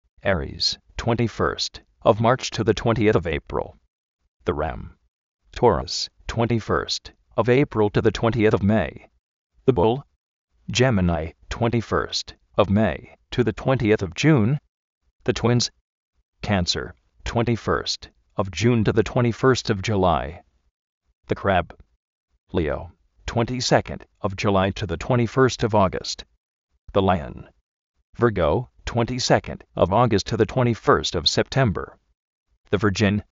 ári:s
tó:res
yéminai
kánser
lí:ou
ví:rgou